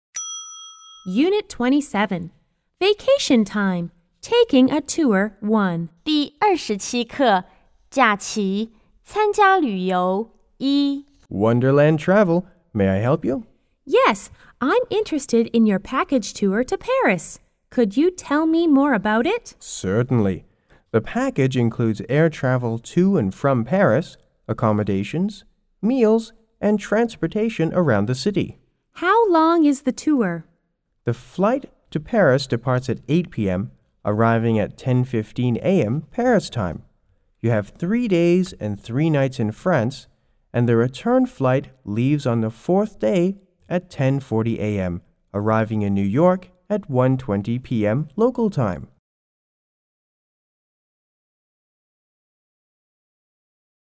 A= Agent C= Customer